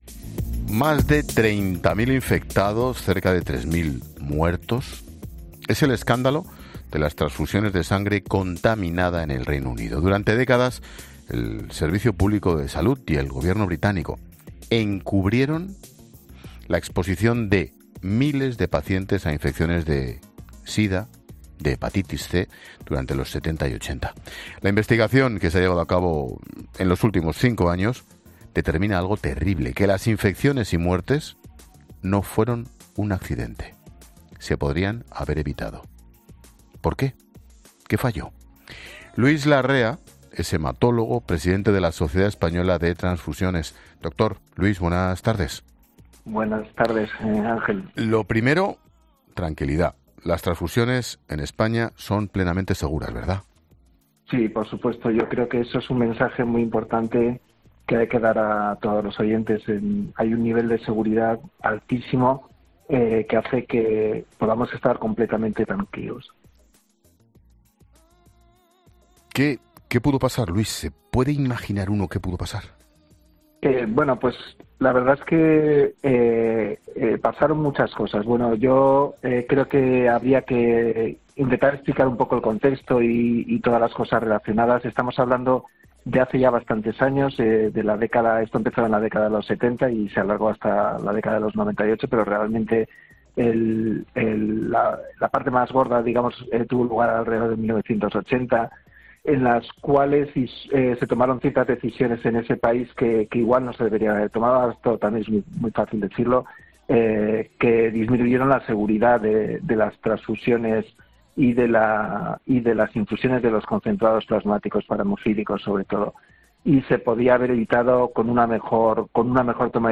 Un hematólogo advierte de la donación que más necesitan los hospitales y pacientes españoles: no es la sangre